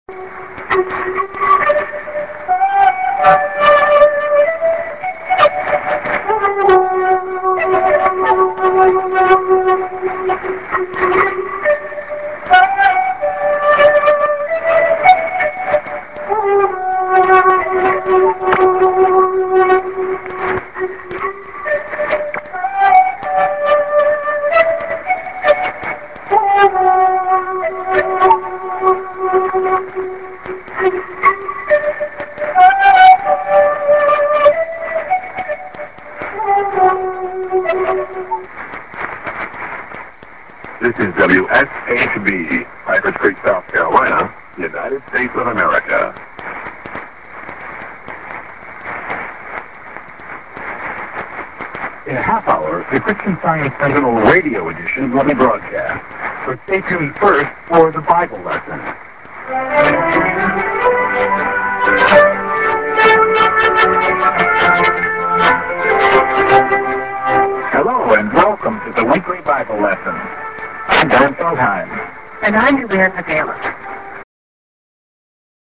St. IS->ID(man)->music->prog